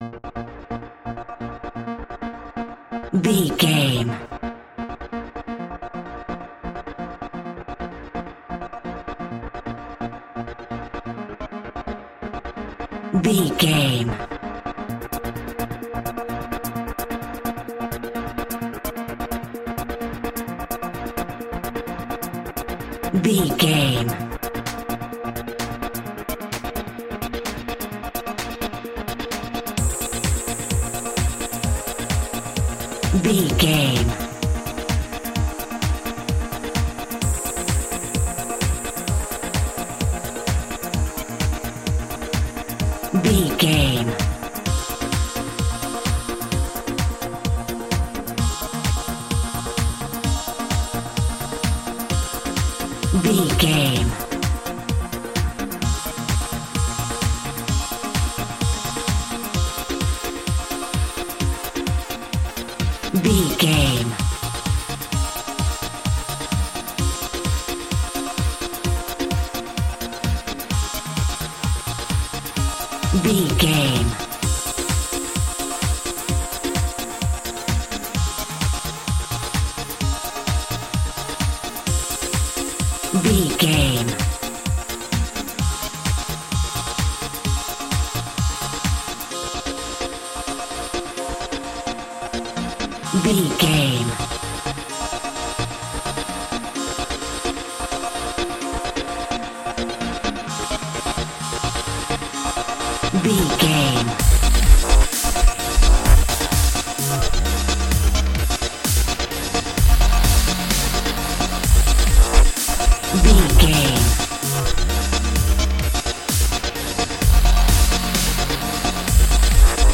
Fast paced
Aeolian/Minor
B♭
aggressive
powerful
dark
driving
energetic
intense
drum machine
synthesiser
breakbeat
synth leads
synth bass